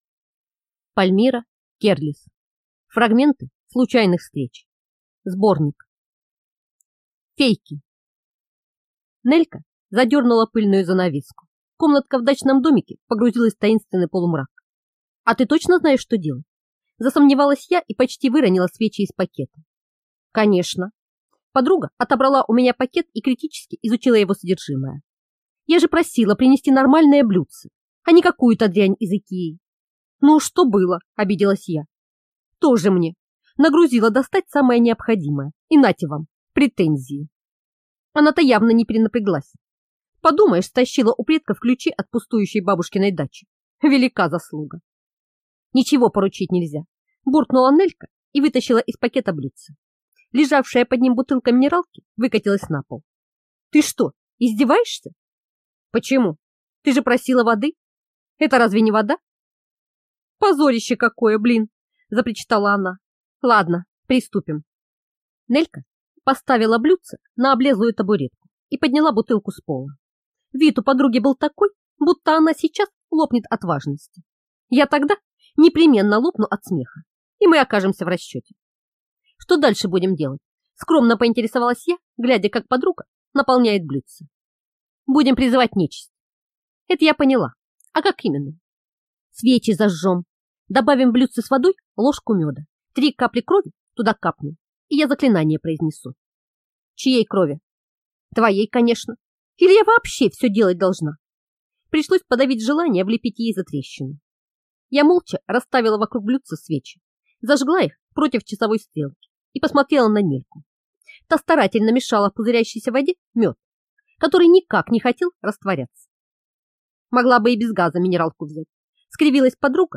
Аудиокнига Фрагменты случайных встреч (сборник) | Библиотека аудиокниг